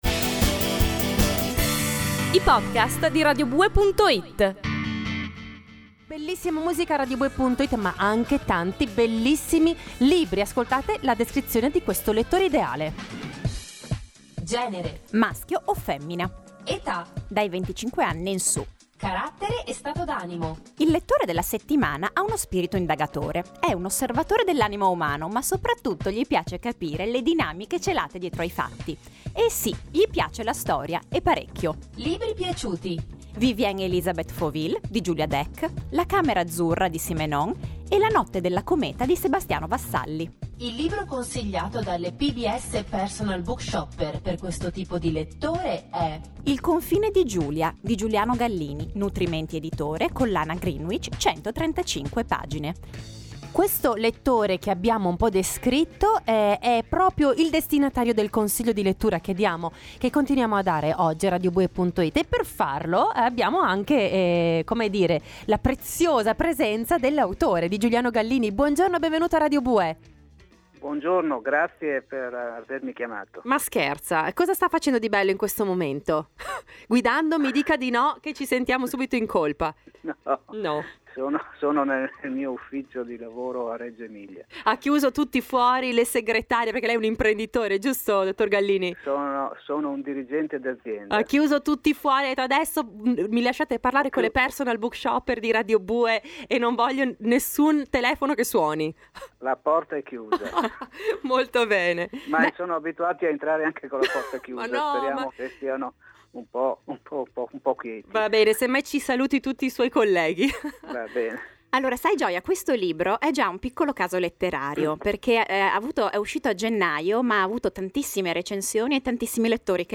E la prima domanda ci pare d’obbligo: come mai un romanzo sulla figura di Ignazio Silone? Ascolta la prima parte dell’intervista (o scaricala qui) e scopri qualcosa in più sulla “polemica siloniana” e su come la letteratura, e la bravura di chi scrive – Silone fu grandissimo narratore -, è in grado di guarire i pregiudizi.